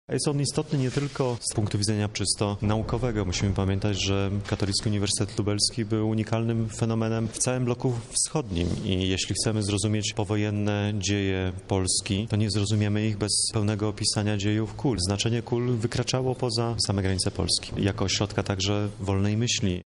Dziś podpisany został list intencyjny w tej sprawie. Rozpoczynamy szeroko zakrojone badania. Planujemy wydanie nawet kilku tomów publikacji na temat KULu – mówi dr Łukasz Kamiński, prezes Instytutu Pamięci Narodowej.